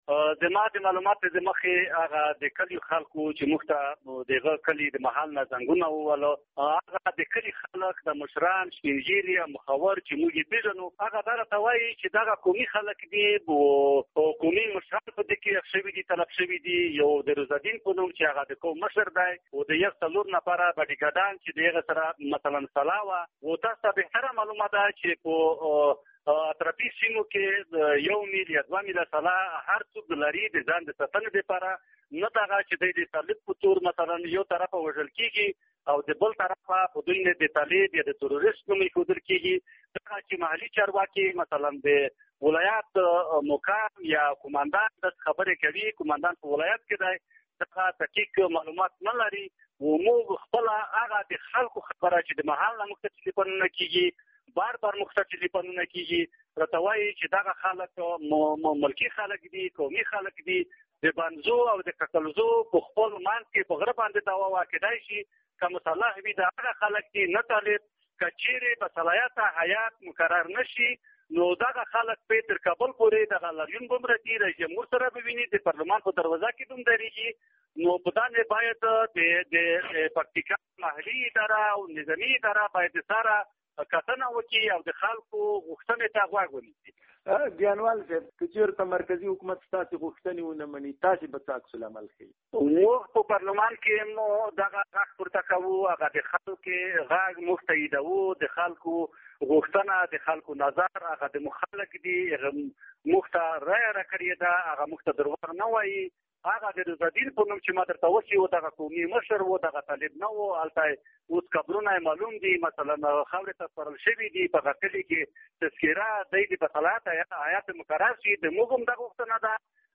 په ولسي جرګه کې د پکتیا له استازي جمعه خان ګیانوال سره مرکه